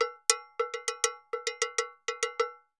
Campana_Salsa 100_3.wav